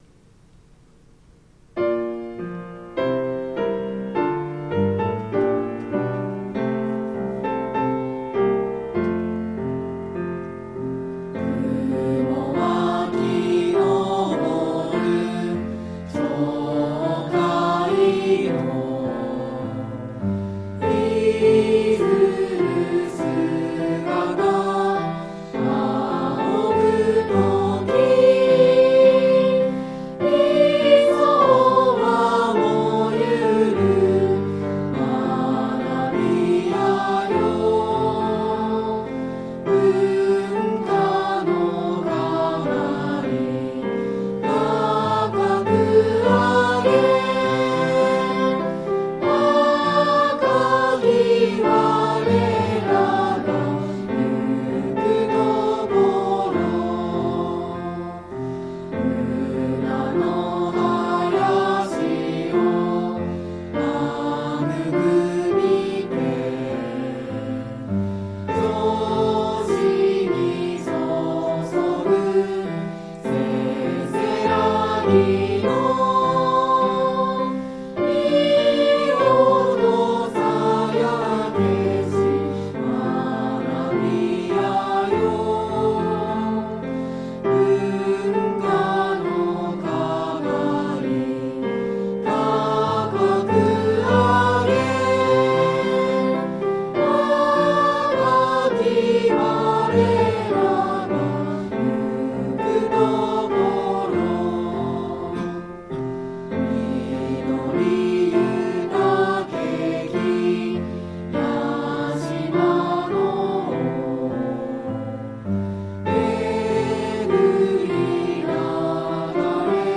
校歌音声